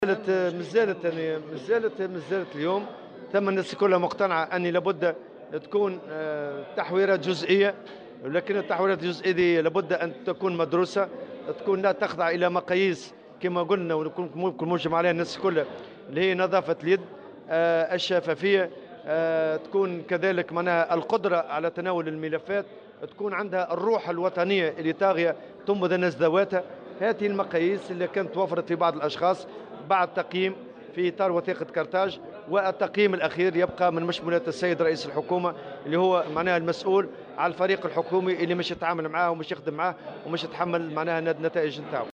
وأضاف الطبوبي في تصريح اليوم لمراسلة "الجوهرة أف أم" على هامش التوقيع على اتفاقية الزيادة في أجور العاملين في وكالات الاسفار إن التحوير يجب أن يكون جزئيا ومدروسا ويخضع لعدة مقاييس من ذلك نظافة اليد والكفاءة والشفافية.